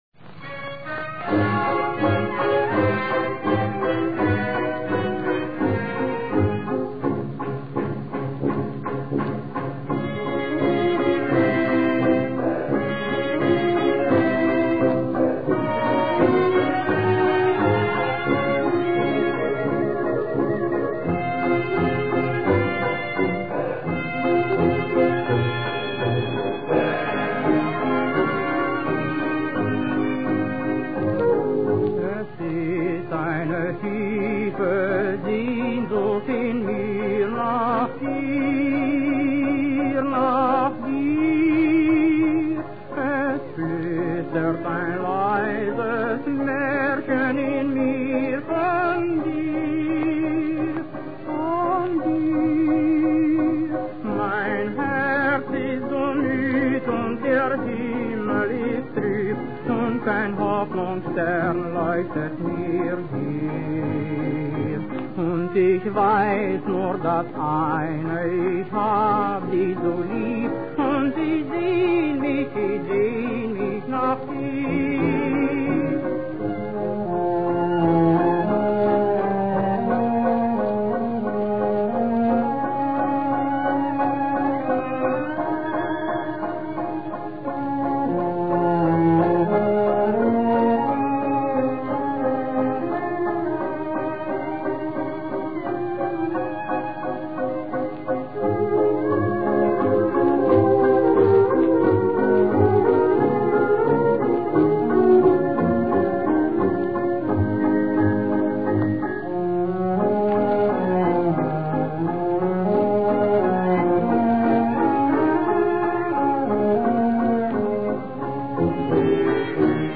ein Blues